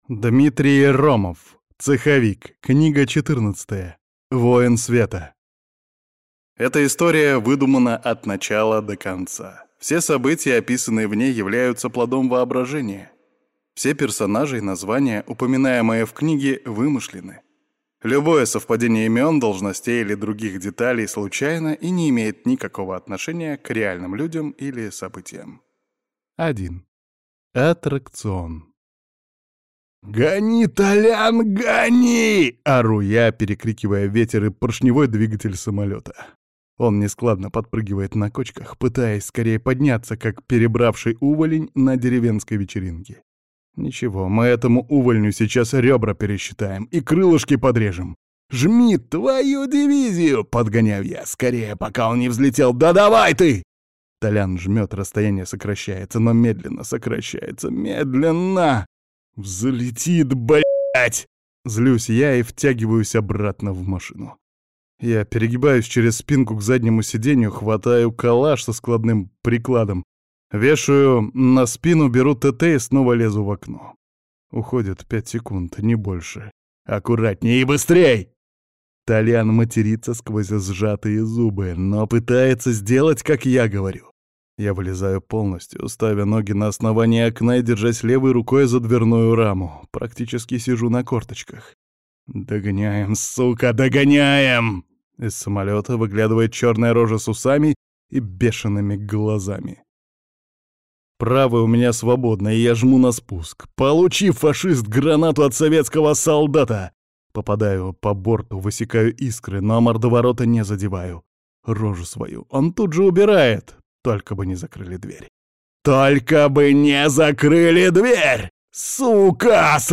Аудиокнига «Сайберия. Пересмешник».
Использована музыкальная композиция:
Отличное исполнение рассказчика, он прекрасно передает атмосферу произведения.